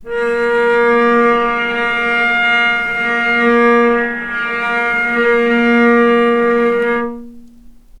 vc_sp-A#3-mf.AIF